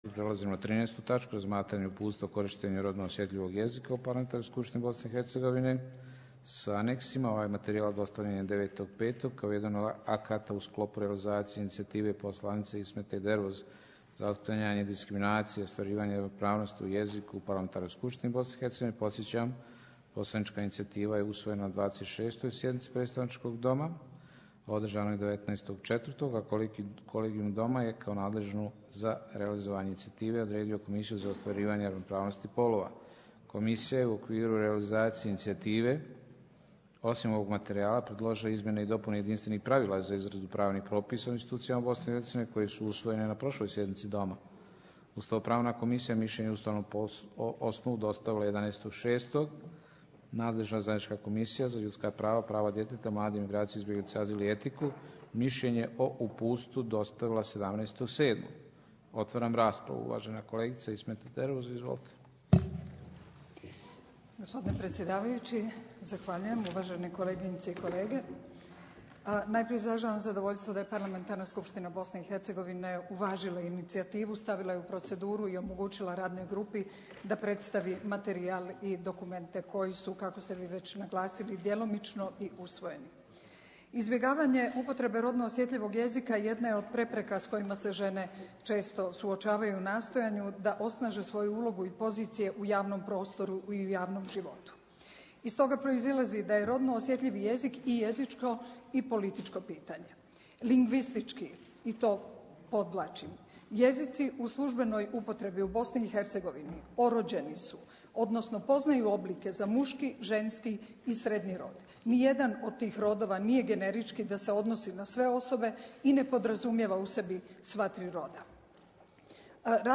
Rasprava_u_PSBIH.mp3